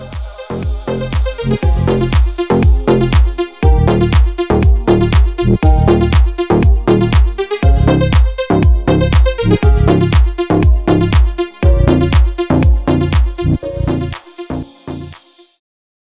• Качество: 321, Stereo
deep house
мелодичные
без слов